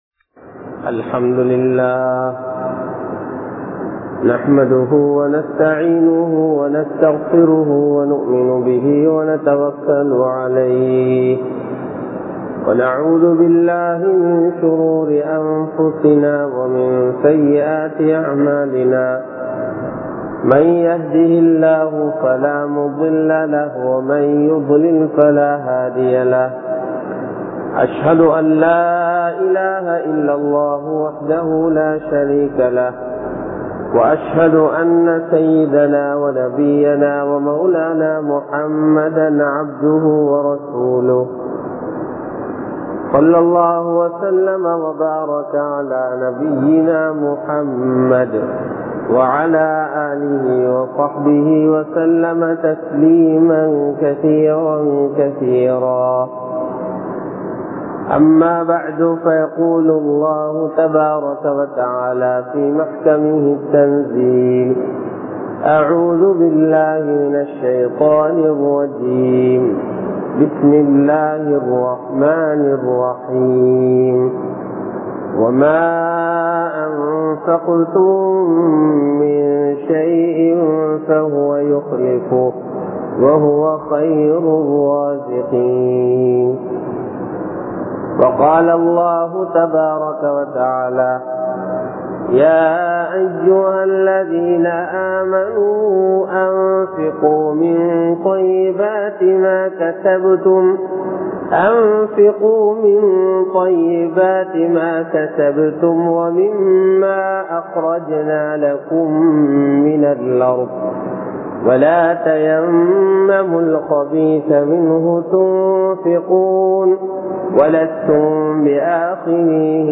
Selvam Oru Amaanithamaahum (செல்வம் ஒரு அமானிதமாகும்) | Audio Bayans | All Ceylon Muslim Youth Community | Addalaichenai